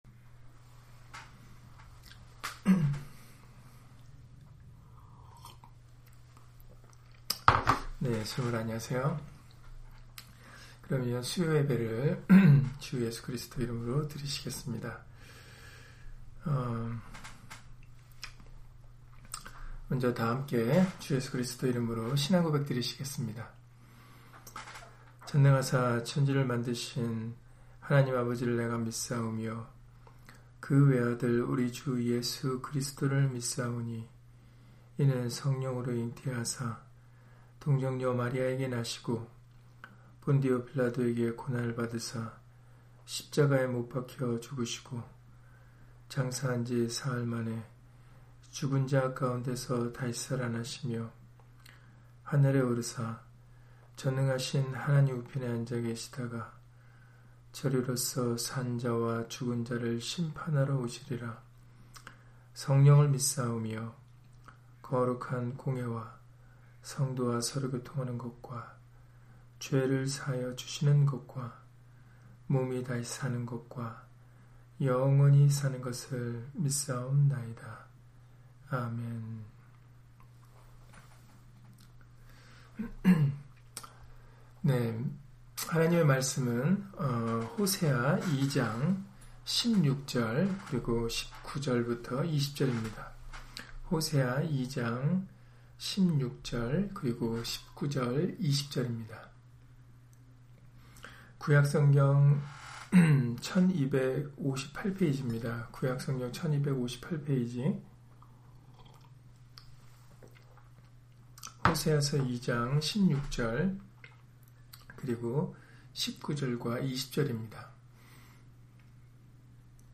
호세아 2장 16, 19-20절 [우리의 한 남편되시는 예수님] - 주일/수요예배 설교 - 주 예수 그리스도 이름 예배당